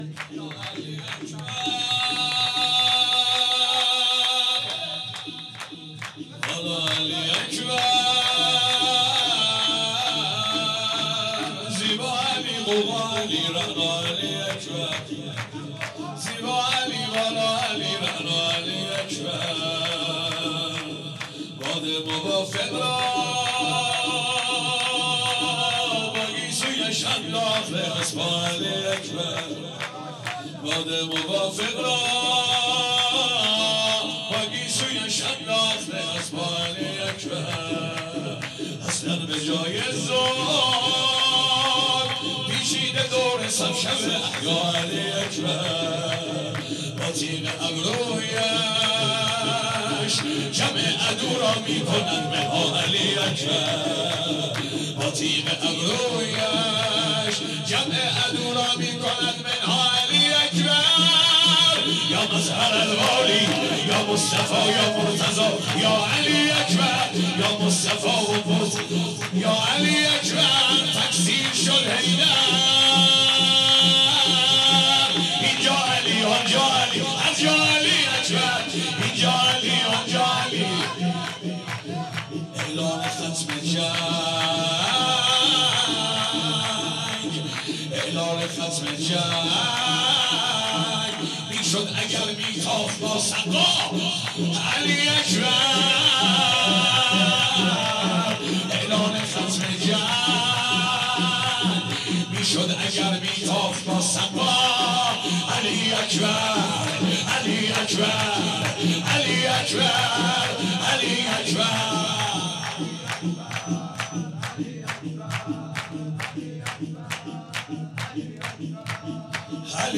جشن ولادت حضرت علی اکبر(ع) ۹۸ شور